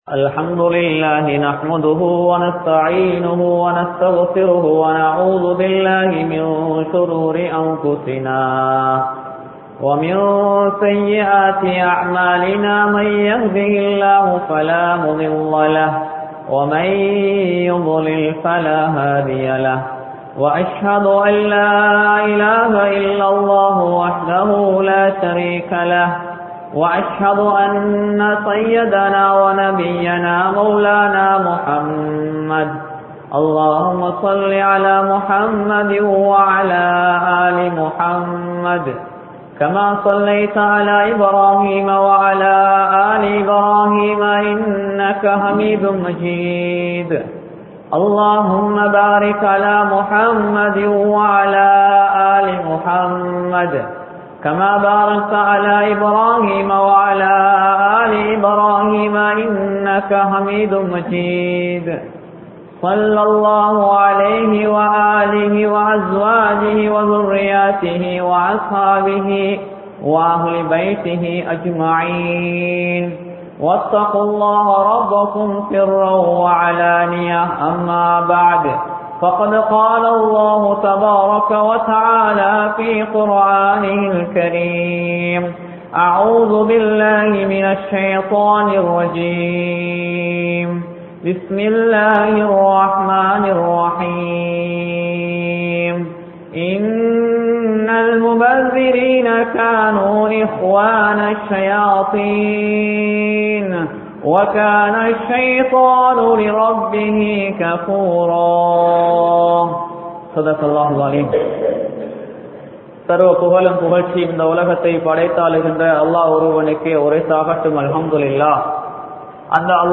Paavangalin Vilaivuhal (பாவங்களி்ன் விளைவுகள்) | Audio Bayans | All Ceylon Muslim Youth Community | Addalaichenai
Siyambalagaskottuwa Jumua Masjidh